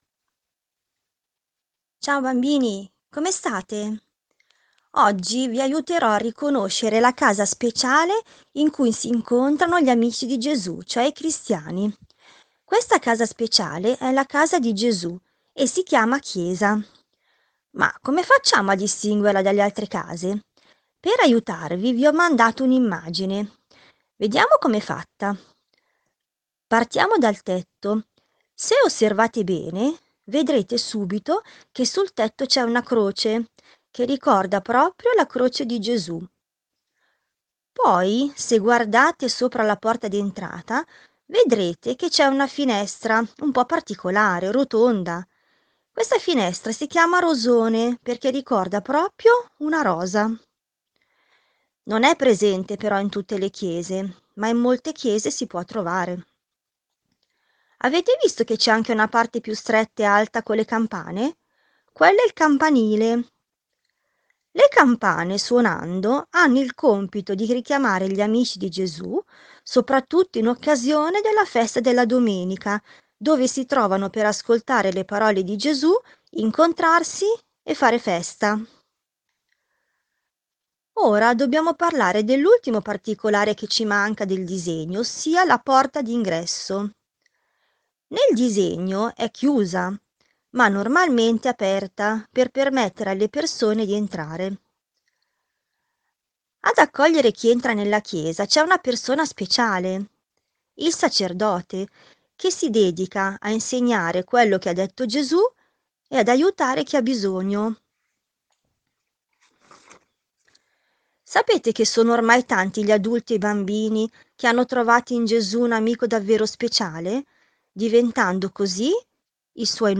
Audio lezione sul riconoscimento della chiesa come edificio, attraverso un'immagine stilizzata, e sul significato della Chiesa come Comunità cristiana.